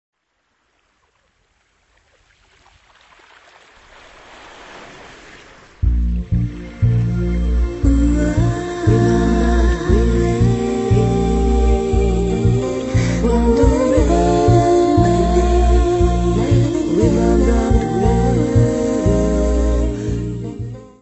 voz.
: stereo; 12 cm
Área:  Pop / Rock